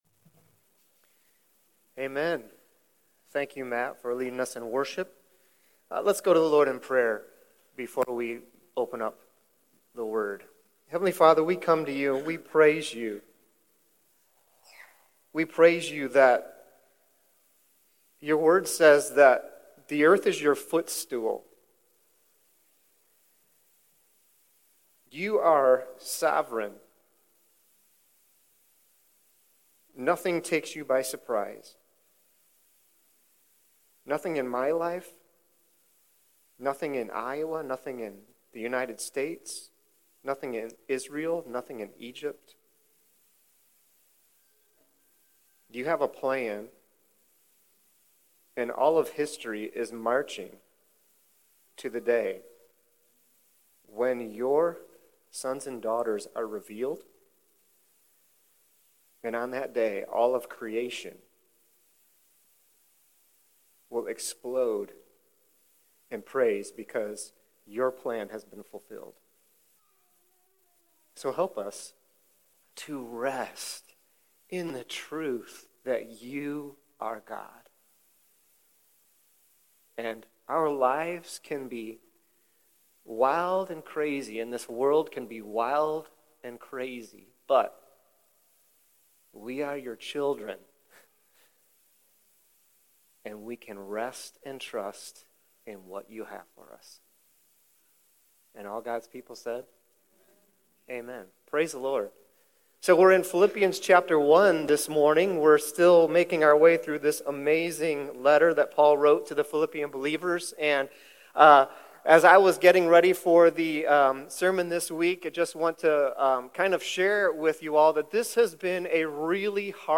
Sermon Questions Share with the group some ways you practice the art of prayer?